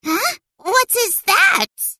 Tags: Everquest 2 Ratonga emote Heals me I dont think soes